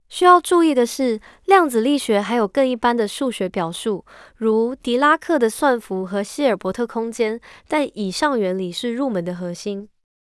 pipeline_tts_ecf9de10.wav